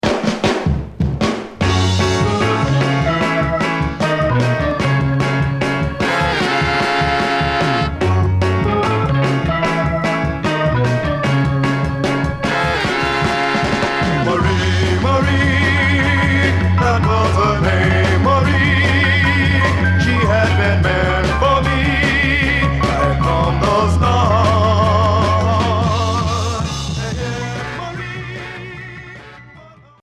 Chansons et 60's beat